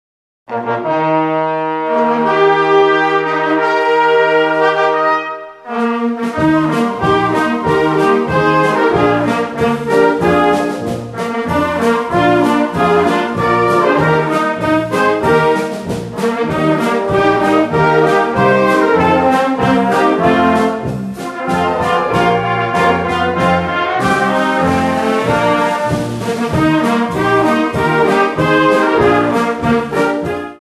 Dziecięco-Młodzieżowa Orkiestra Dęta w Krobi
Obecny skład orkiestry to 50 grających muzyków.
orkiestra.mp3